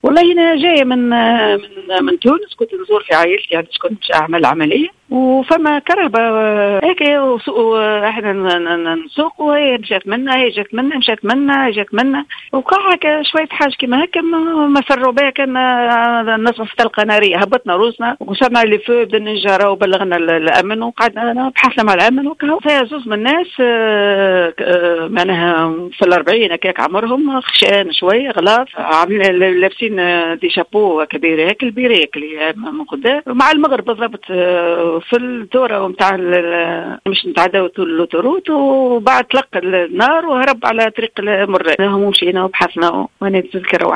Dans une déclaration accordée à Jawhara FM ce samedi 27 février 2016, l'ex députée d'Ennahdha, Fattoum Attia a assuré avoir été la cible de coups de feu par deux individus qui étaient à bord d'une voiture qui la suivait au niveau de l'autoroute Tunis-Hammamet.